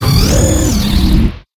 Cri de Dolman dans Pokémon HOME.